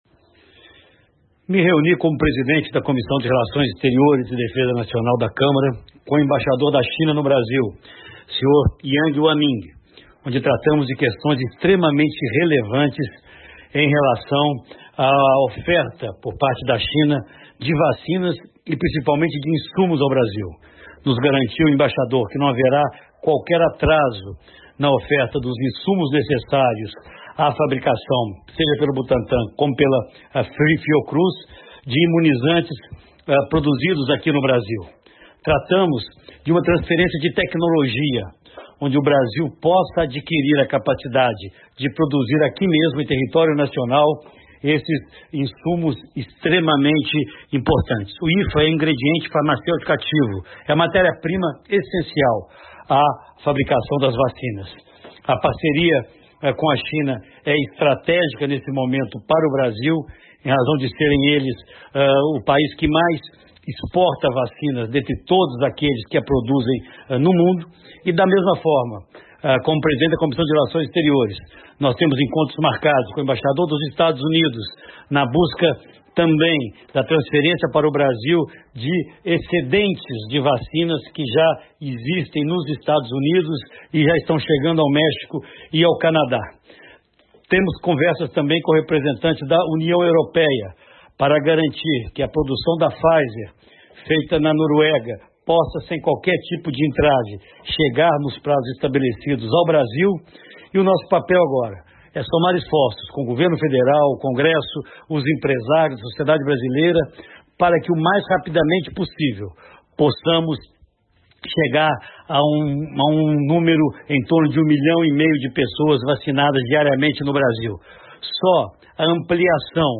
Aécio fala após reunião com o embaixador da China, Yang Wanming